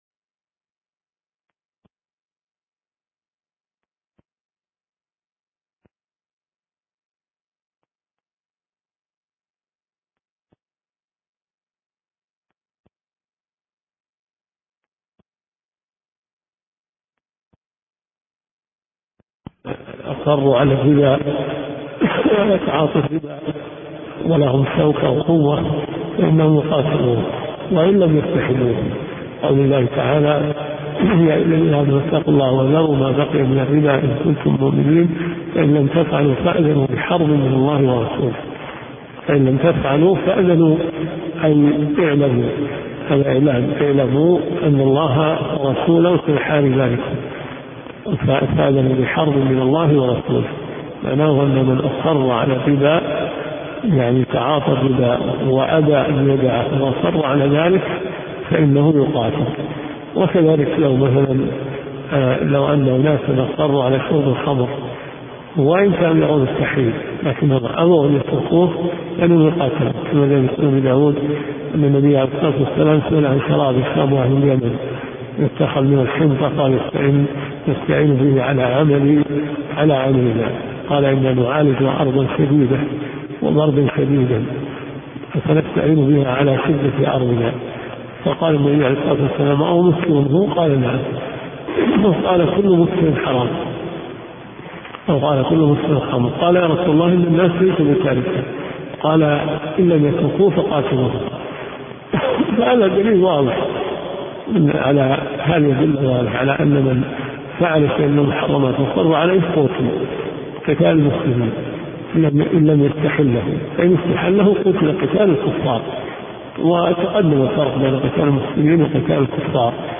دورة صيفية في مسجد معاذ بن جبل .